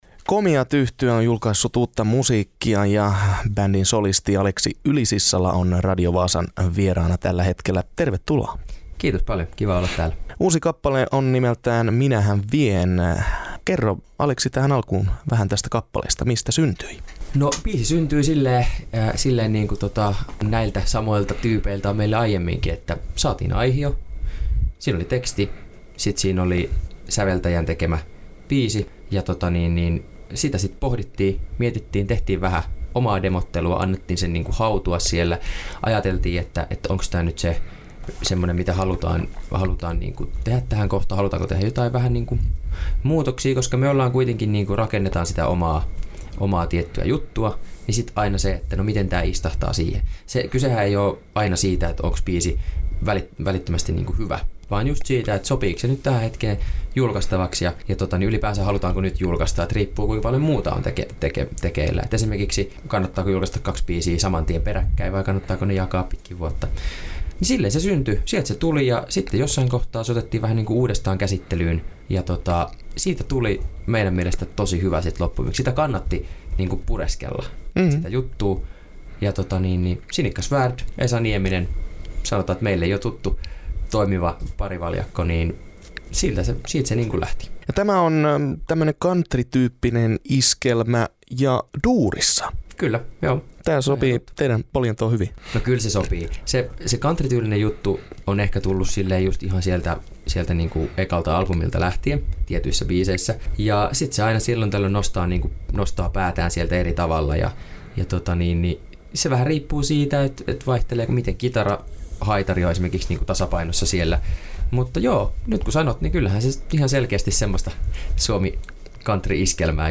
vieraili Radio Vaasan studiolla.